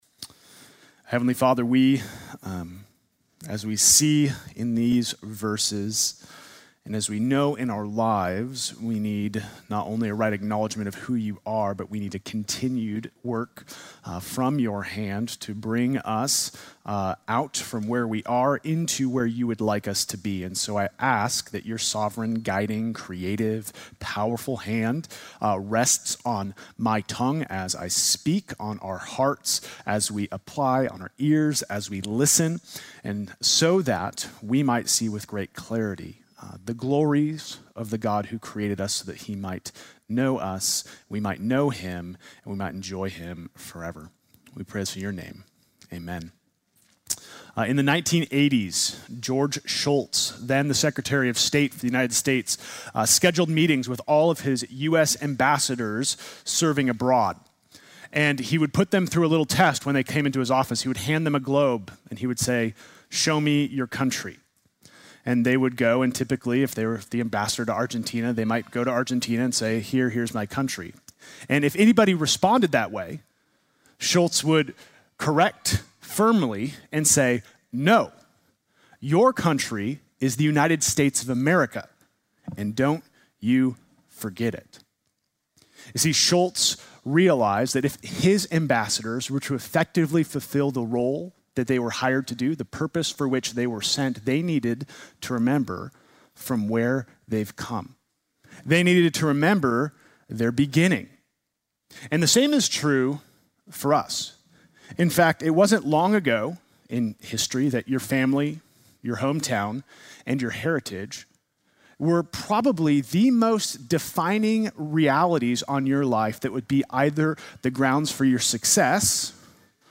Sunday morning message July 27